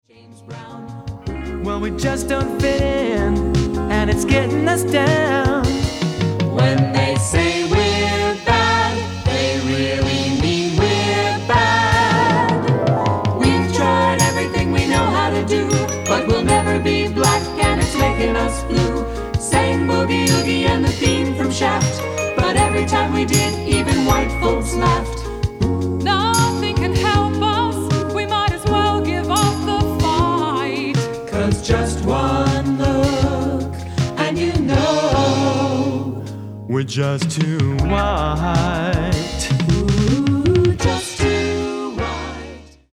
- our only STUDIO recording!